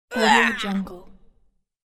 دانلود افکت صدای جیغ دختر رزمی‌کار 1
افکت صدای جیغ دختر رزمی‌کار 1 یک گزینه عالی برای هر پروژه ای است که به صداهای انسانی و جنبه های دیگر مانند ناله زن، جیغ دختر و کاراته کار نیاز دارد.
Sample rate 16-Bit Stereo, 44.1 kHz
Looped No